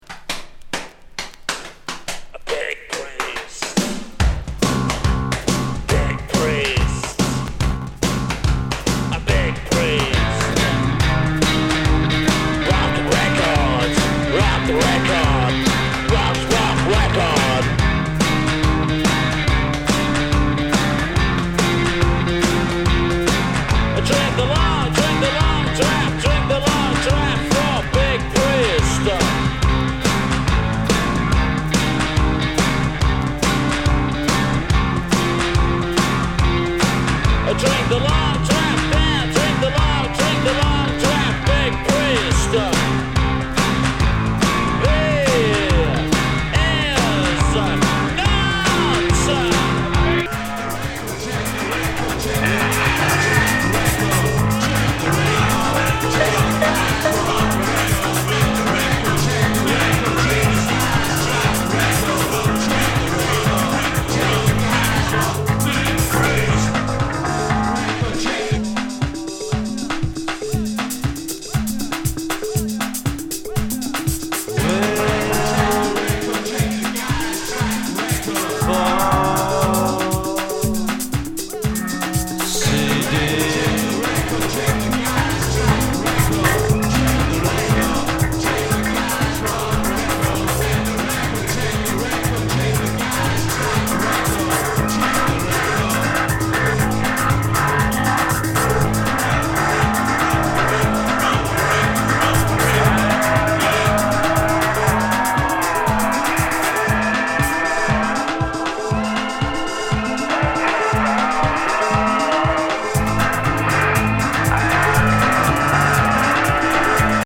マンチェスターのポスト・パンク・グループ88年、ガレージィ
インダストリアル・エレクトリック・ボディ・グルーブ